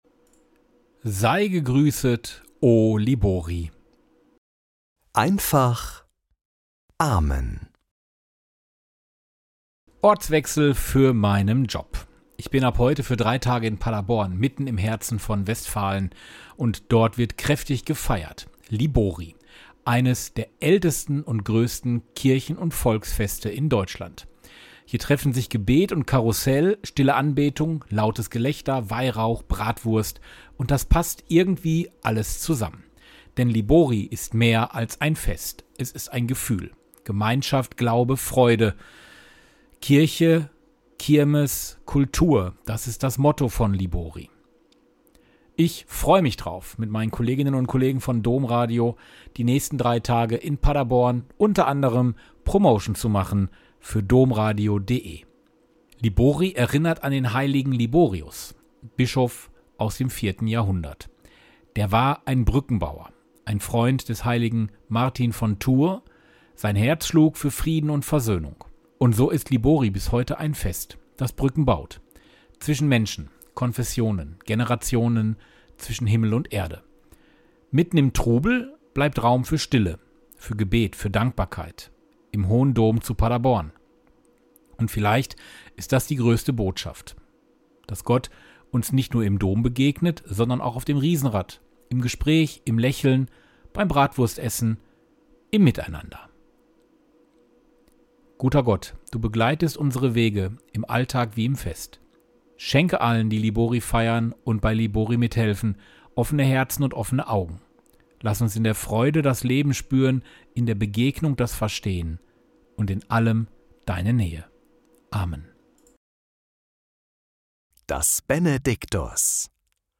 Morgenimpuls in Einfacher Sprache
Der Podcast mit Gebeten, Impulsen und Gedanken - in einfacher Sprache.